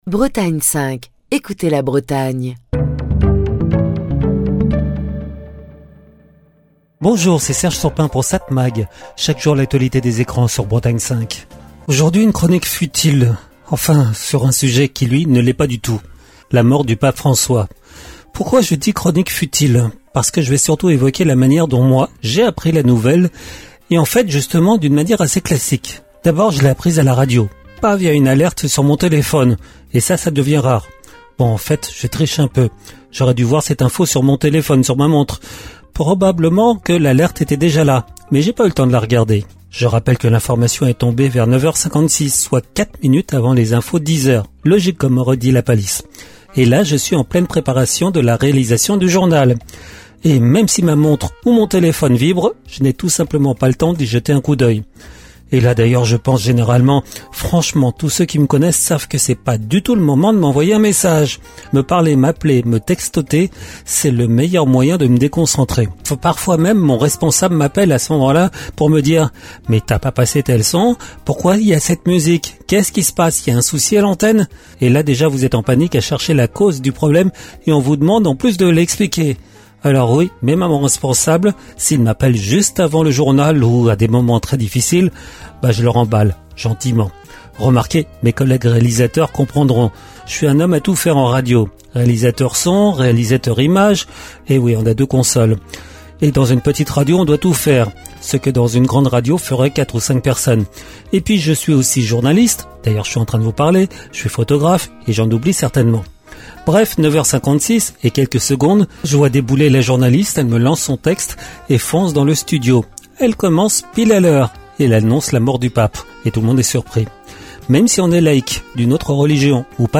Chronique du 23 avril 2025.